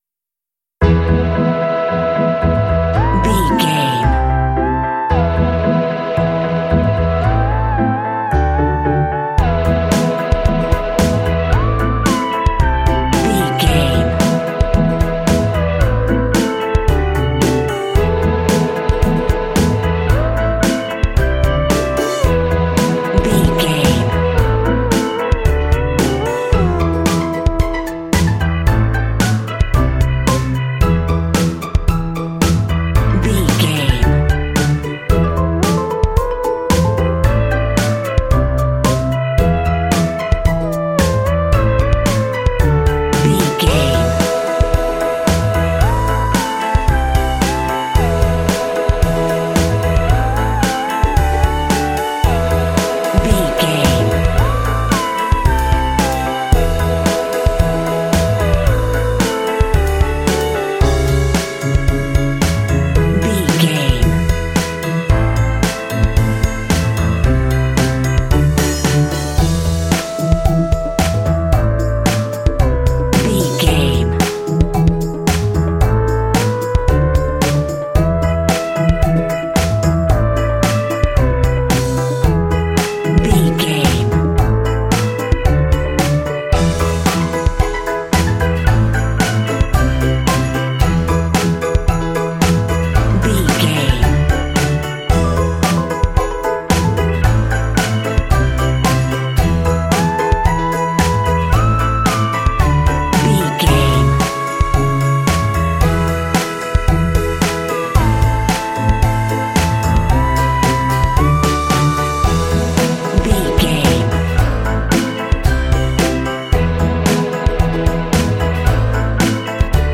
Aeolian/Minor
tension
energetic
lively
strings
drums
piano
synthesiser
electric organ
contemporary underscore